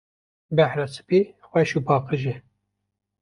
Read more Frequency B2 Pronounced as (IPA) /spiː/ Etymology From Proto-Iranian *cwaytáh, from Proto-Indo-Iranian *ćwaytás.